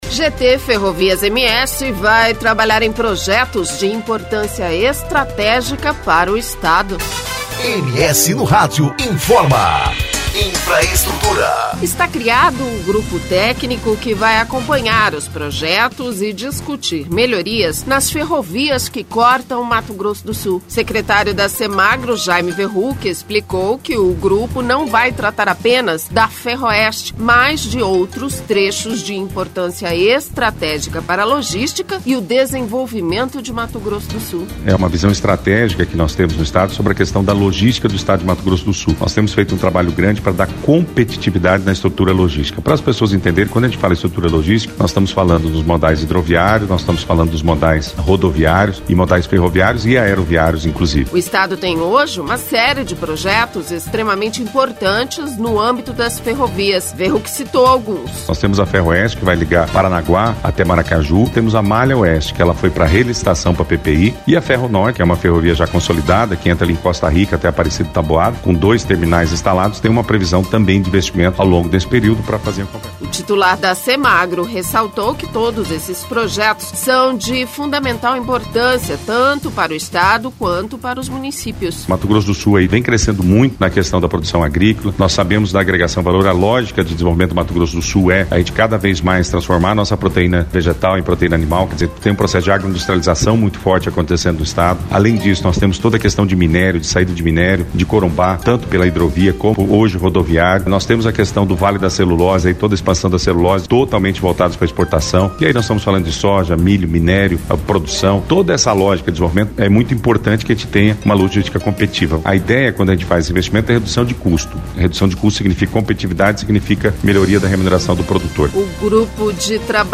O assunto foi um dos destaques desta terça-feira do radiojornal MS no Rádio da Educativa FM 104.7. O secretário de Meio Ambiente